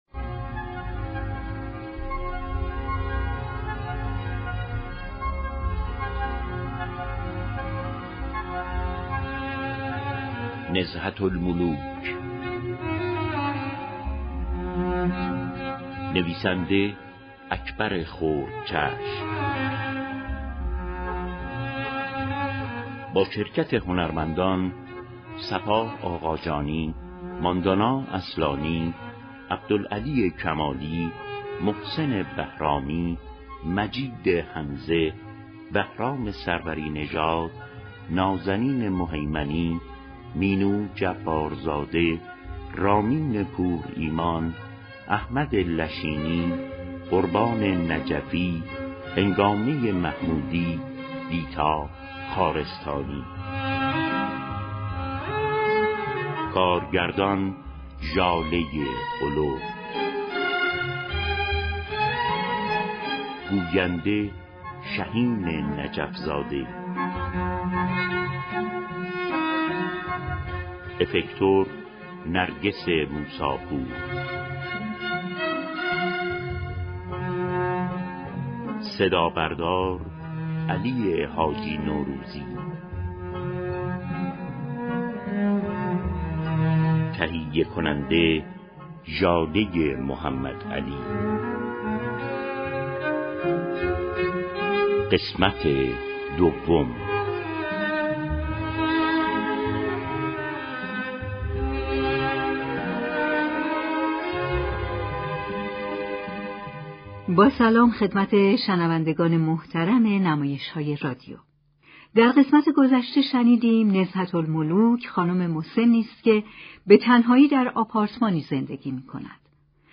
ششم اسفند ماه ، شنونده نمایش جدید رادیویی
به گزارش روابط عمومی اداره كل هنرهای نمایشی رادیو، این سریال جدید رادیویی به نویسندگی